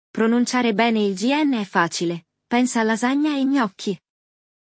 O “GN” no italiano tem som de “NH”, como em “nhoque” e “lasanha”.
A pronúncia é justamente essa: “NHÃ”!